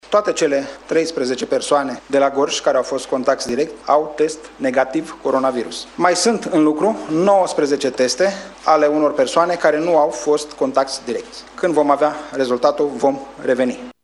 Secretarul de stat în Ministerul Sănătăţii Nelu Tătaru a declarat în urmă cu puțin timp că toate testele făcute persoanelor din Gorj, care au avut contact direct cu cetăţeanul italian depistat pozitiv cu noul coronavirus de autorităţile din Italia, sunt negative.